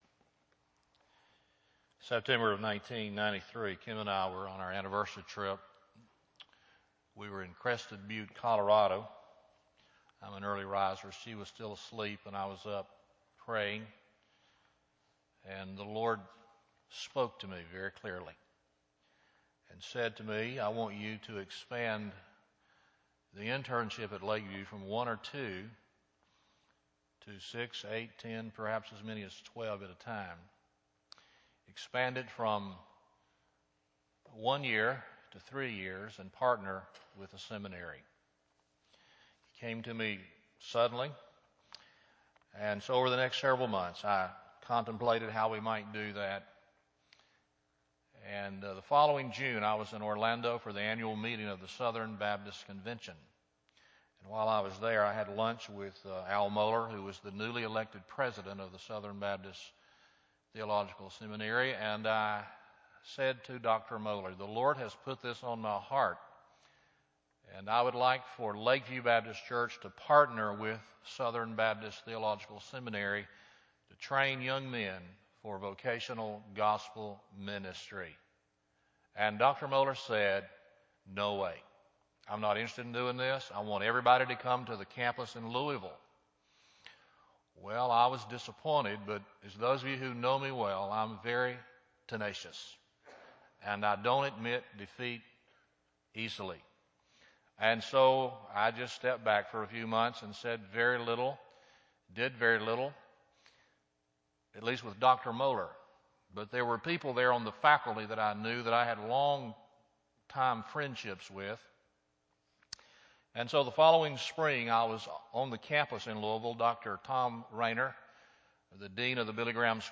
Intern Ordination Service